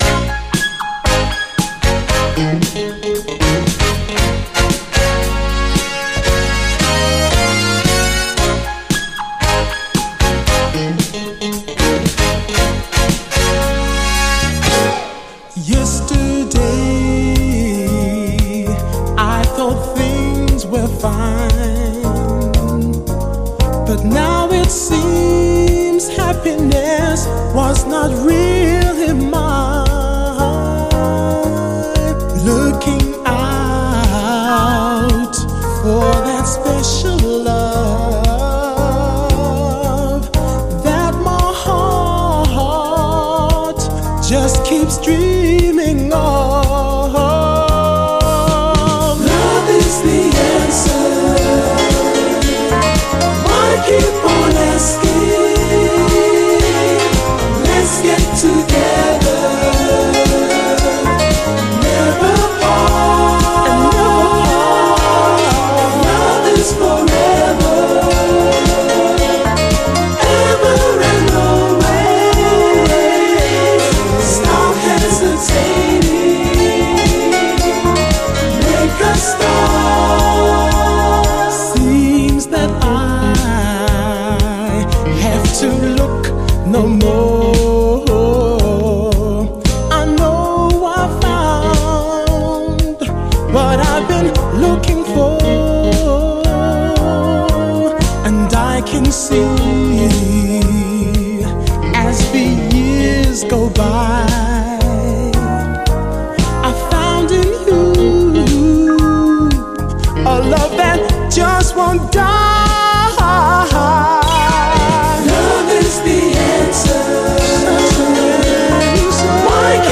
SOUL, 70's～ SOUL, 7INCH
抜群にキャッチーなイントロ、そしてエレピ＆ピアノが演出するエレガンス！